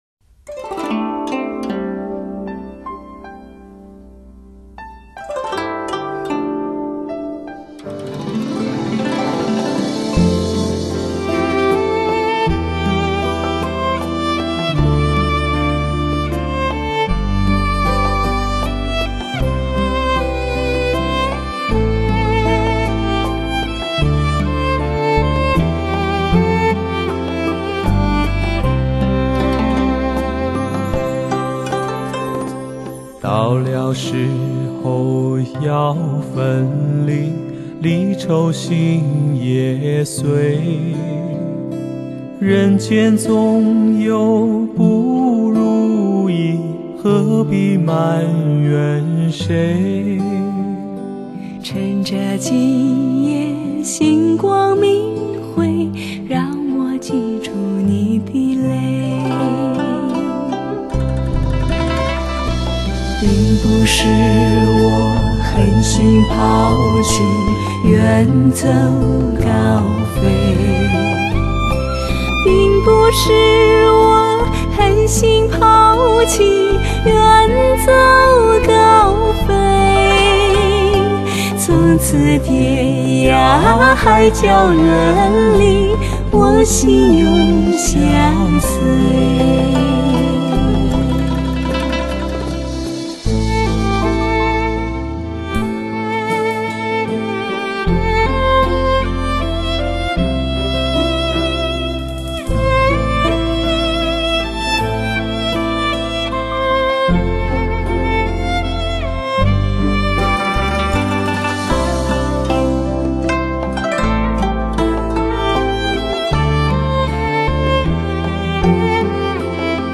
男女对唱